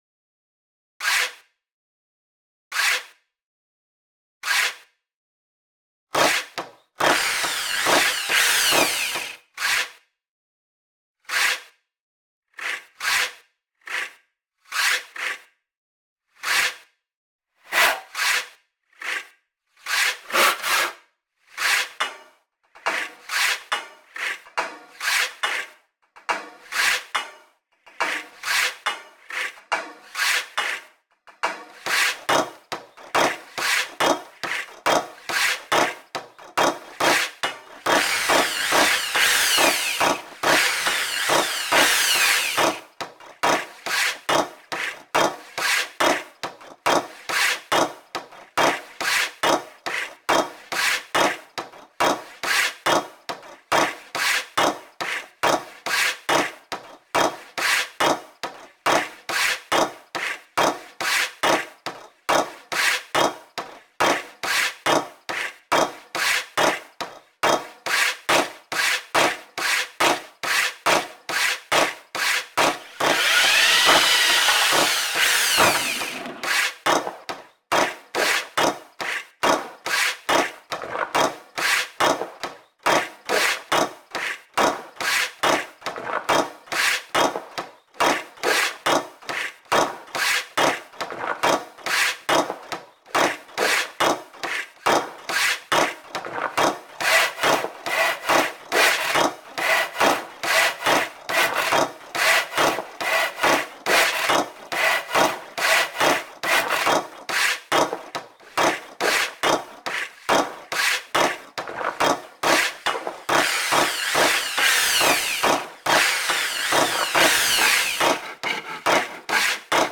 A musical experiment in ‘rhythmic D-I-Y’ beats, set to the video of the couple of weeks taken to restore a Victorian porch door in a Welsh chapel.
Instead, I sample the video soundtracks then throw them all as a bespoke drumkit into Hydrogen, to make something far nearer to my actual experience.
This piece is a set of about two-dozen samples organised using Hydrogen to create a beat track. The only way to replicate the rhythm I was working to – using old trestles on some uneven ground – was to do it in the dotted-half beats of a compound 6/4 time signature (weirdly, the same signature as Chopin’s ‘Nocturne No.1’, but this is a bit faster).